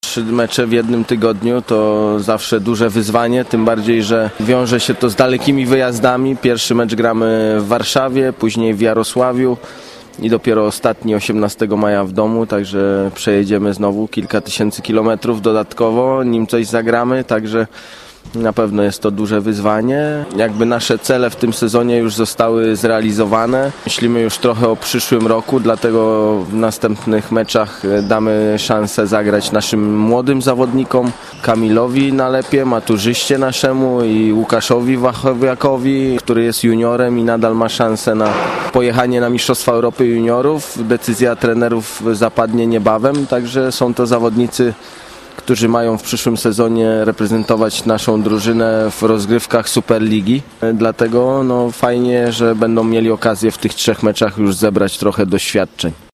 O najbliższych meczach mówi kapitan ZKS-u Lucjan Błaszczyk: